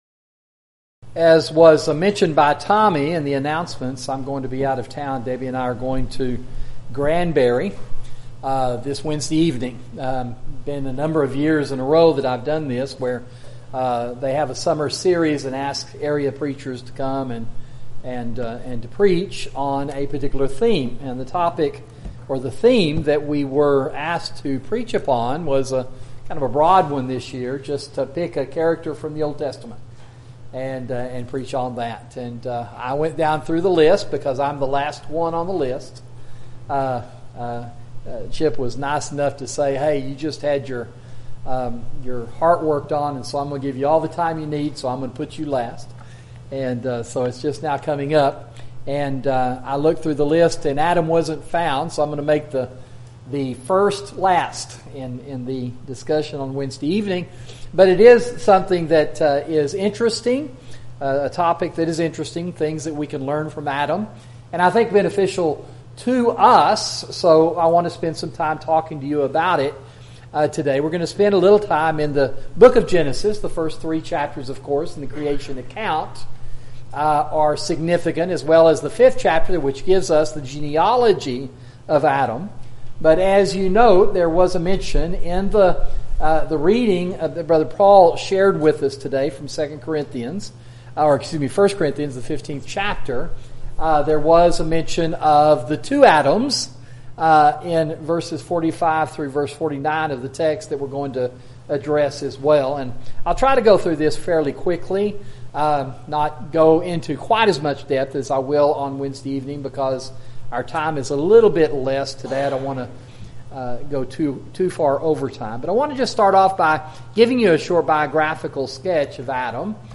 Audio PPTX (Right Click to SAVE) YouTube Video of Sermon <<———><><———>> Share this: Tweet